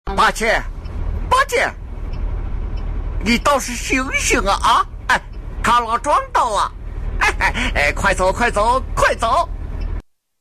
孙悟空叫八戒音效_人物音效音效配乐_免费素材下载_提案神器